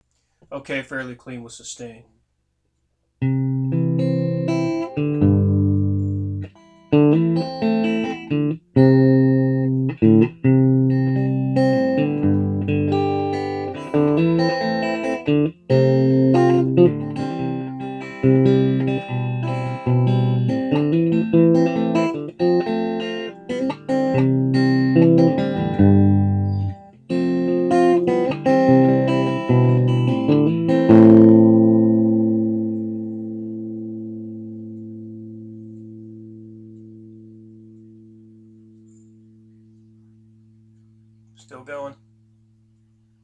It may come as a surprise to some, but she can be remarkably clean and sweet.
• Both of these cuts are recorded with the same junky headset mike as above, into a laptop pc.
• Gain is set to about 1, with just enough Master Volume to get a decent listening level.
• Guitar volume set at max, which really helps sustain and sounds best to me.
Clean with Lots of Sustain
1-clean_sustain.wav